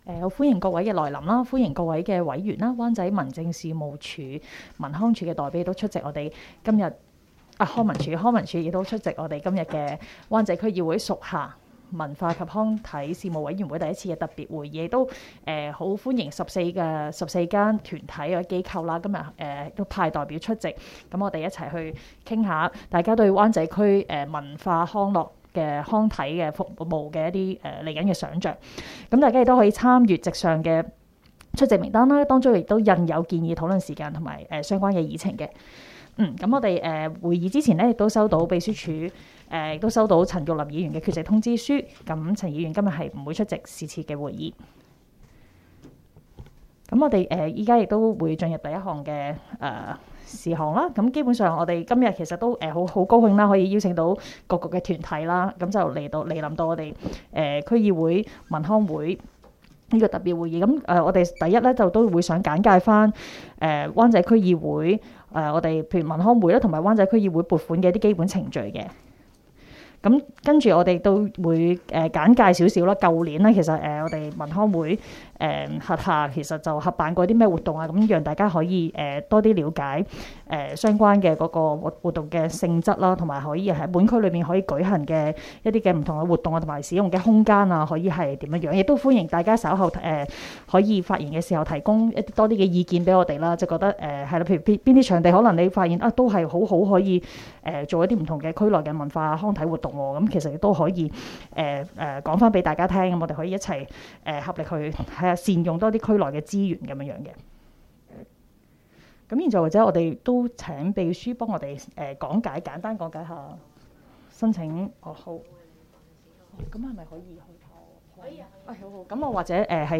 委员会会议的录音记录
地点: 香港轩尼诗道130号修顿中心21楼 湾仔民政事务处区议会会议室